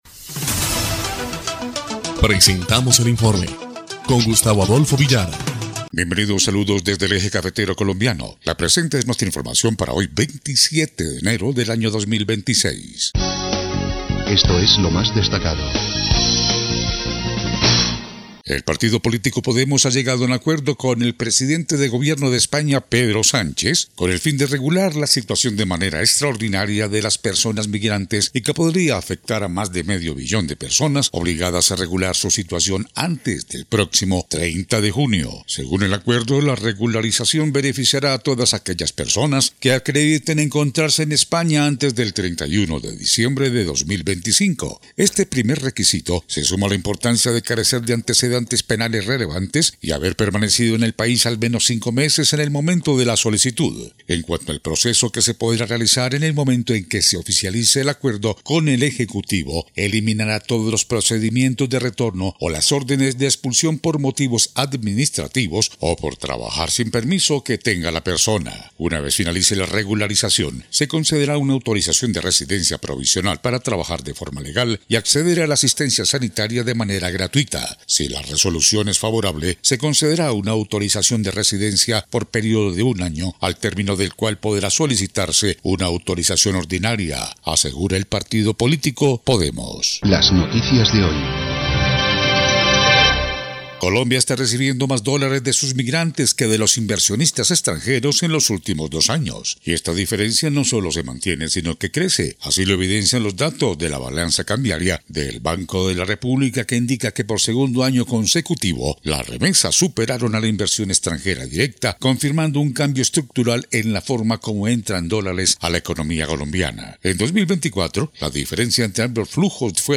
EL INFORME 3° Clip de Noticias del 27 de enero de 2026